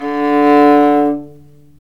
Index of /90_sSampleCDs/Roland L-CD702/VOL-1/STR_Viola Solo/STR_Vla3 % + dyn
STR VIOLA 01.wav